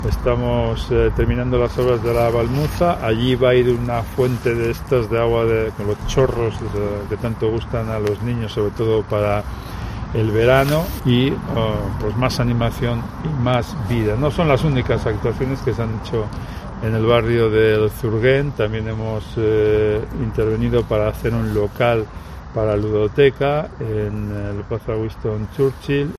Carlos García Carbayo explica la actuación en el parque de La Valmuza, en el Zurguén